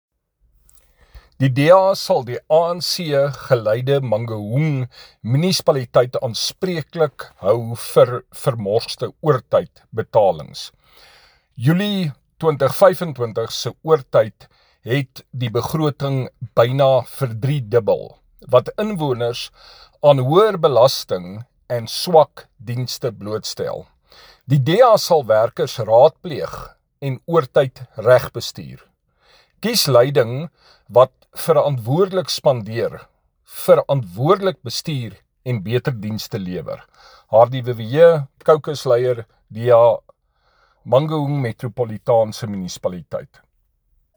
Afrikaans soundbites by Cllr Hardie Viviers and